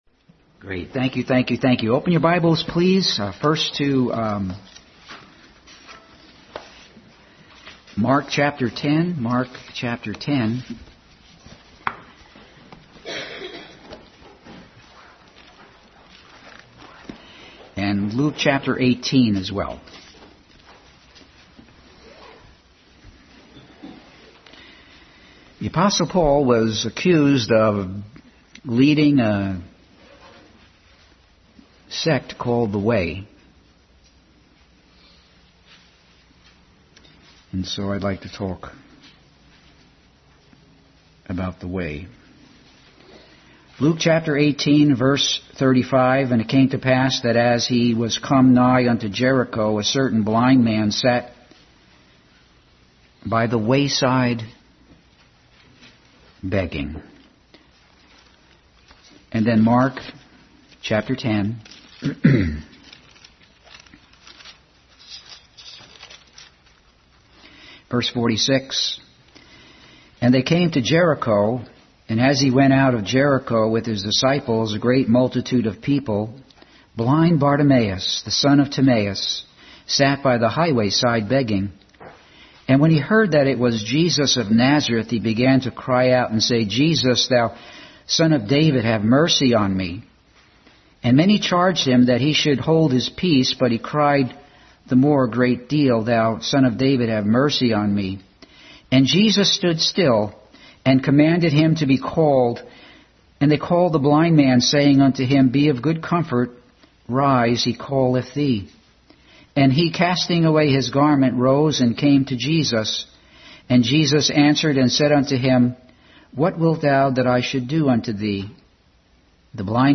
Bible Text: Mark 10:46-52, Luke 18:35-41, Proverbs 30:18-20, Mark 10:1-27 | Family Bible Hour message.